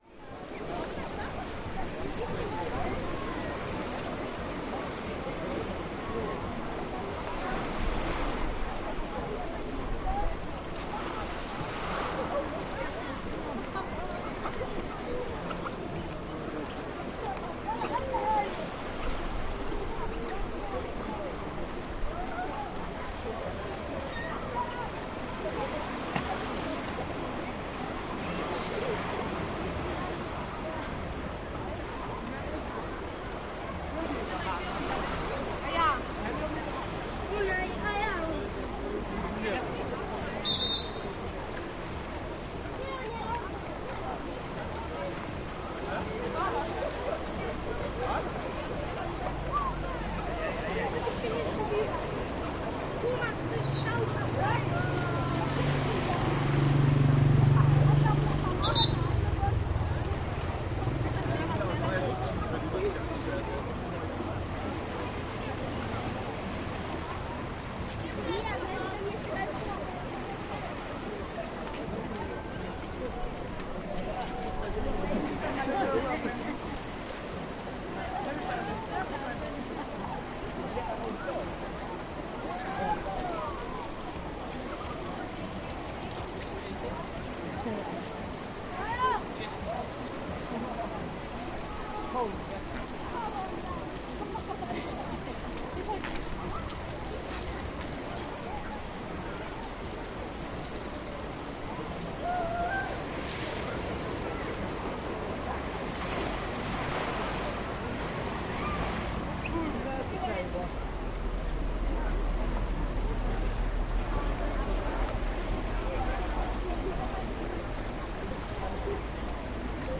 Звуки аквапарка
Шум людей и звуки воды в аквапарке